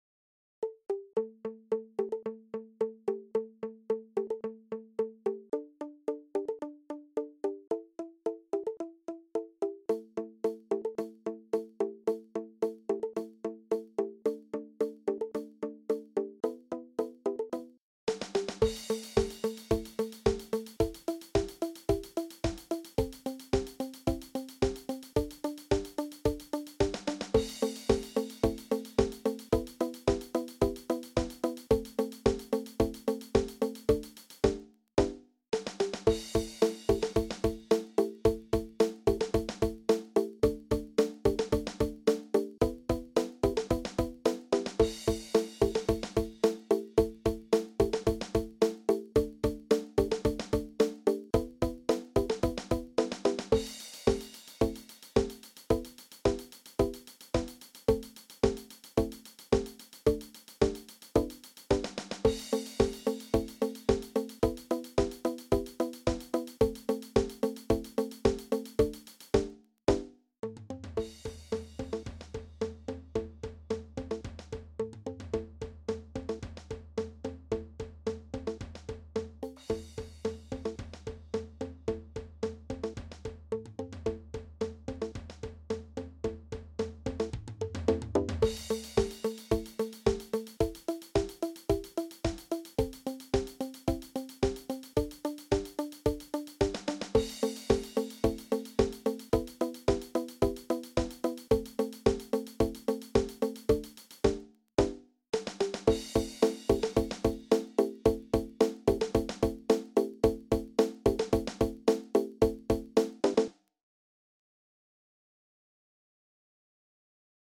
Percussion Ensemble
fun and simple pop tune to be played by boom whackers